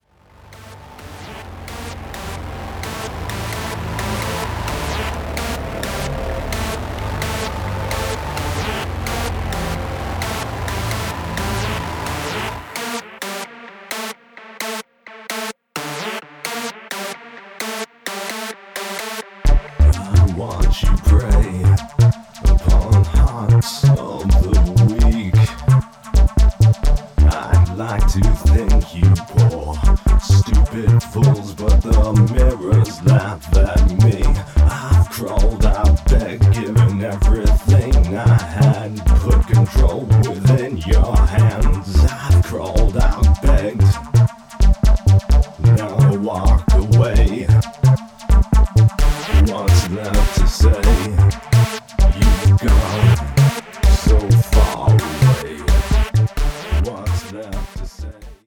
Batcave / Deathrock, Darkwave
a blend of Industrial with Gothic Rock elements
guitar